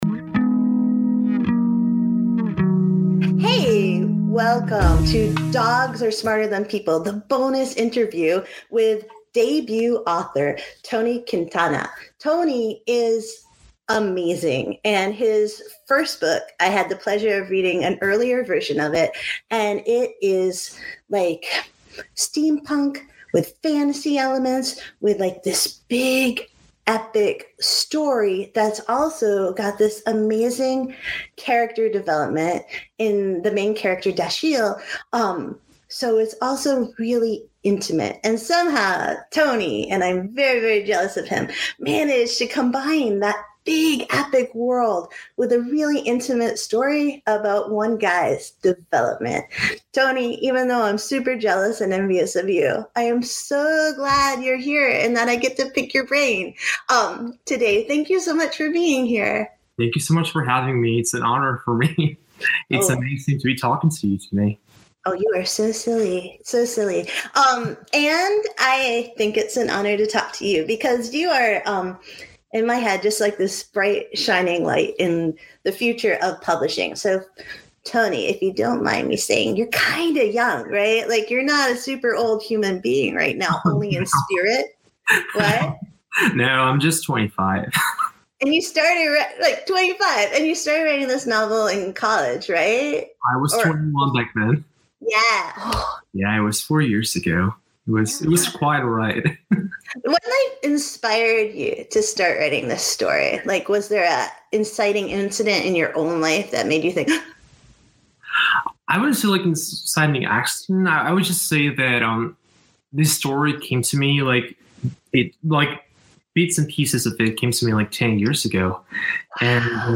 Sublime Steampunk. Author Interview